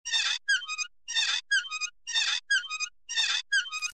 Звук скрипучих качелей 3